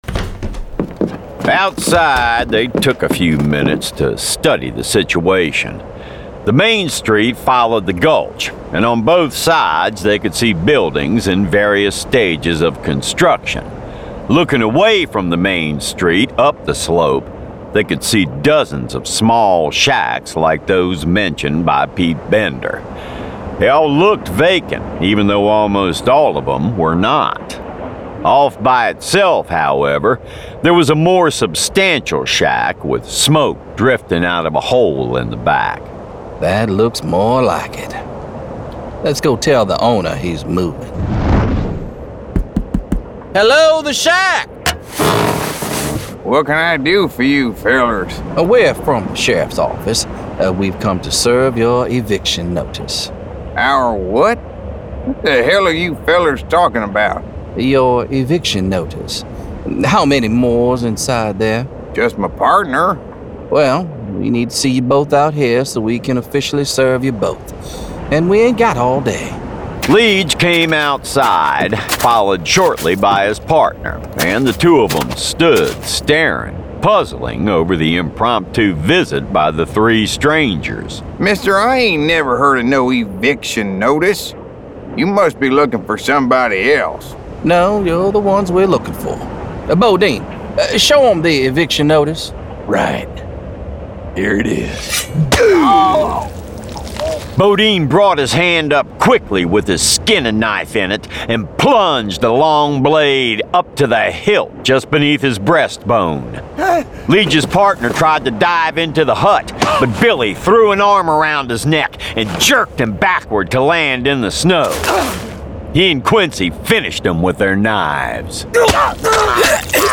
Full Cast. Cinematic Music. Sound Effects.
Genre: Western